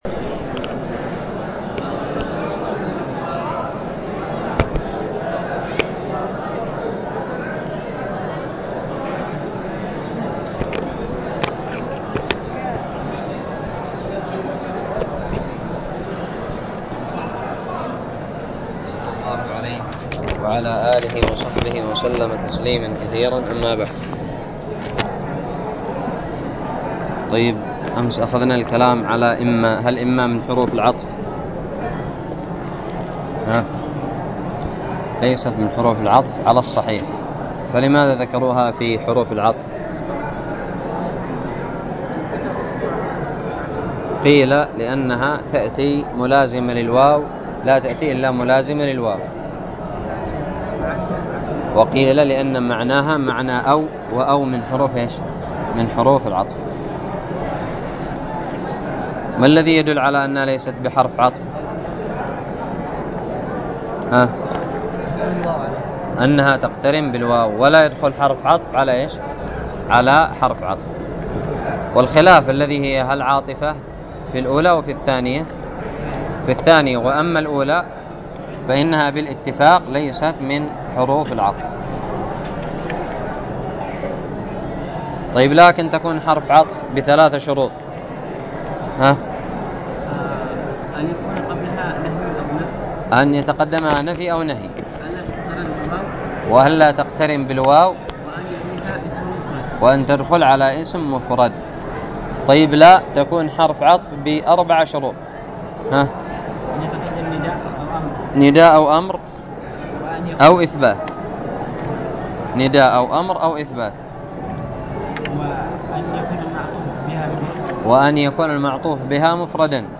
ألقيت بدار الحديث بدماج